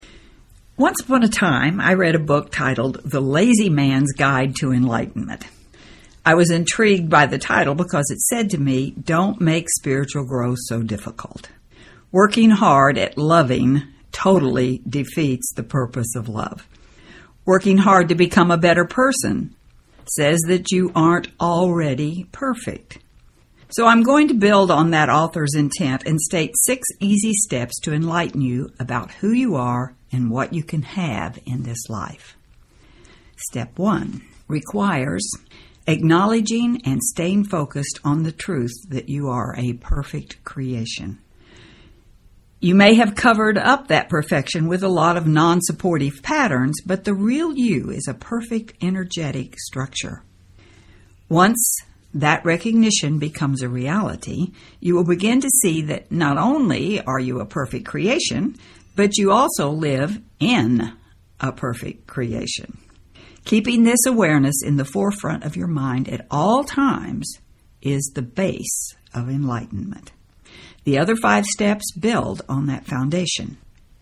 You will become aware of how and where you forget your Divinity and re-learn how to be more vigilant. It is an audio guidebook to your inner journey – one that cycles through Gratitude, our daily chores, our realness and our illusions, only to end up with Forgiveness (inside and out).